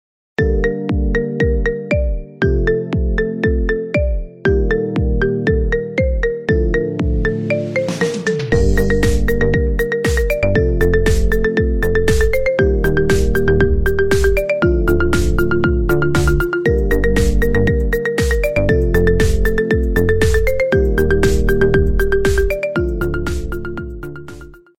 Kategorie Marimba Remix